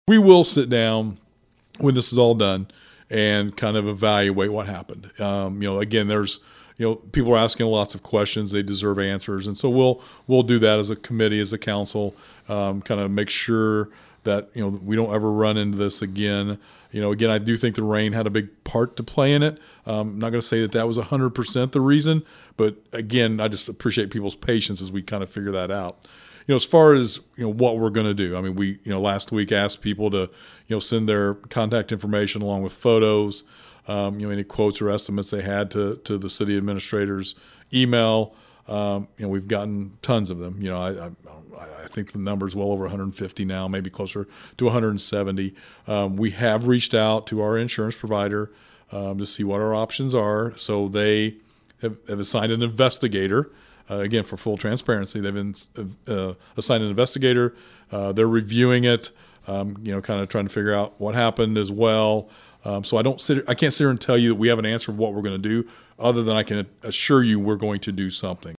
Speaking on our podcast “Talking about Vandalia,” Mayor Knebel says they believed the combination of using a new type of rock and then the heavy rains right after led to the issues.